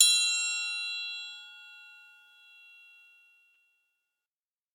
Goblet_G_Medium
bell chime ding dong goblet instrument ping sound effect free sound royalty free Music